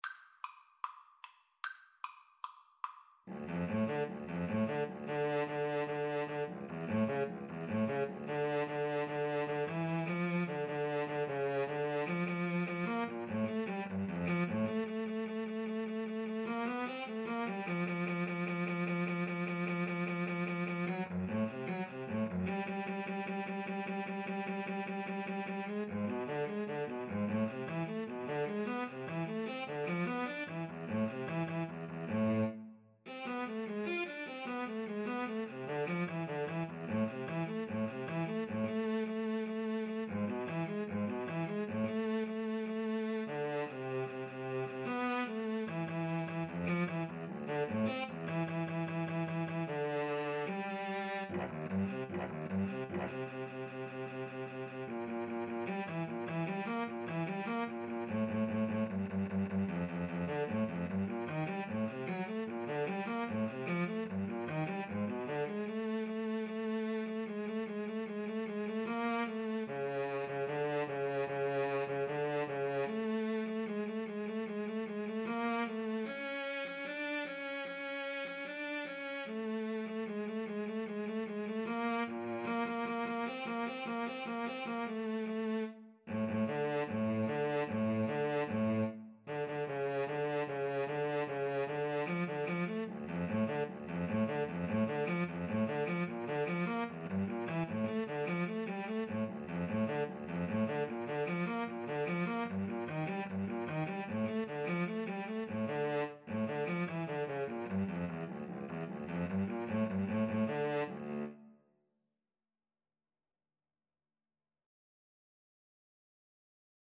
Classical (View more Classical Cello Duet Music)